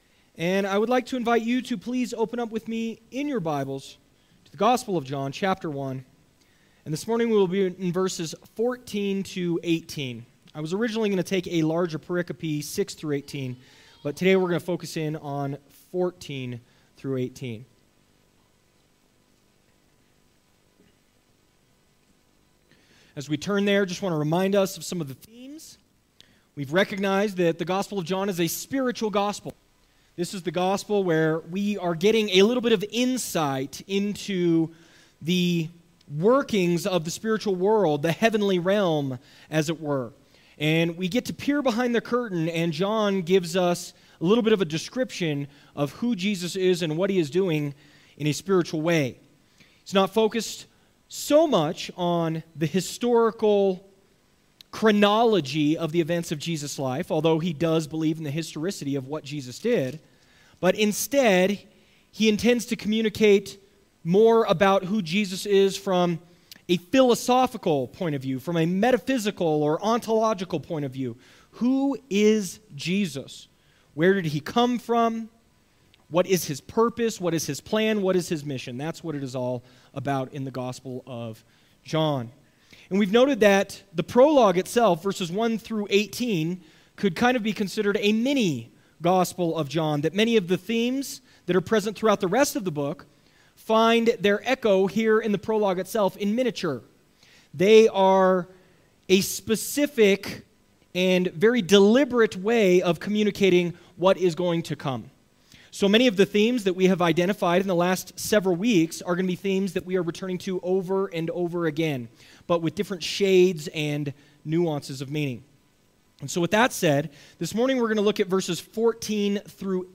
Sermons | First Baptist Church of Leadville
Apologies for the incomplete file - our church lost power several times during the service due to inclement weather.